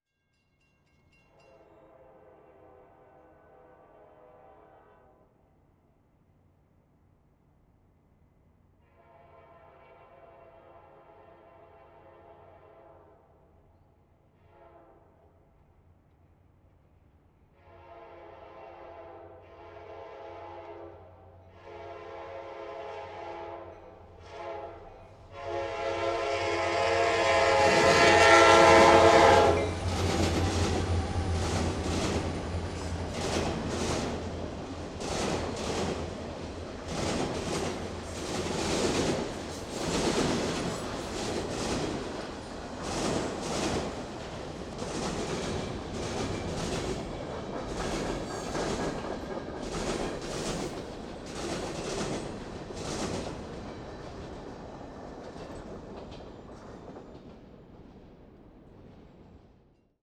Tetrahedral Ambisonic Microphone
Recorded January 21, 2010, at the crossing of the Union Pacific and Austin and Western railroads, McNeil, Texas